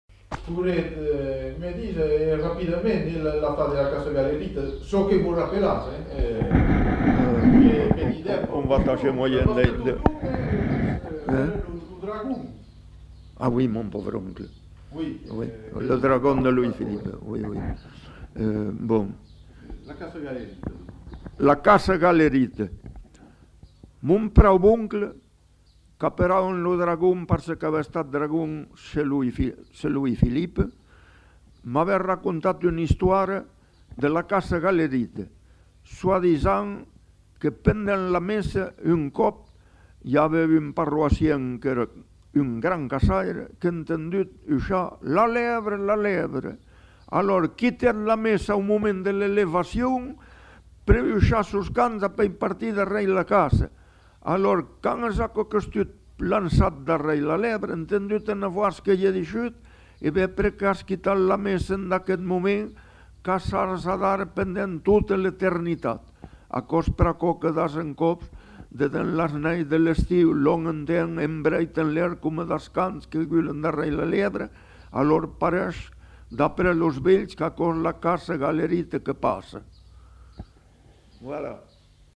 Lieu : Saint-Yzans-de-Médoc
Genre : conte-légende-récit
Effectif : 1
Type de voix : voix d'homme
Production du son : parlé
Classification : récit légendaire